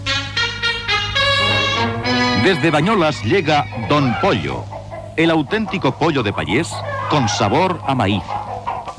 Anunci de Don Pollo